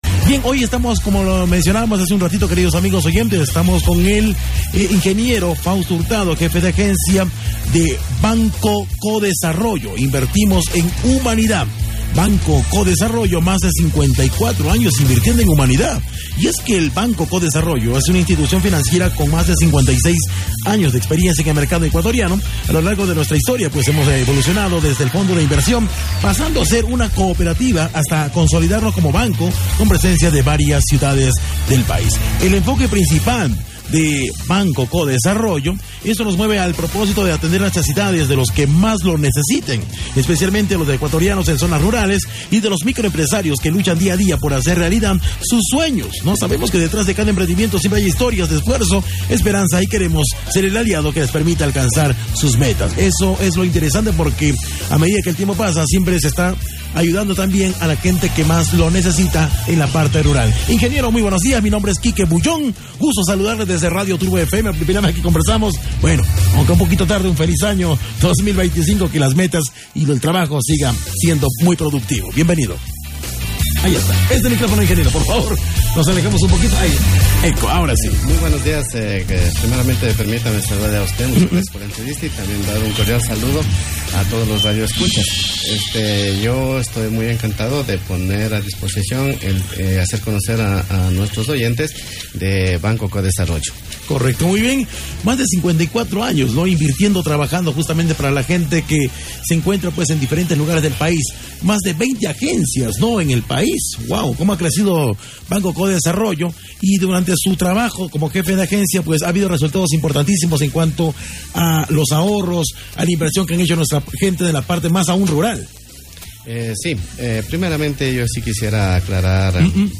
Zum Radio-Interview (mp3-file)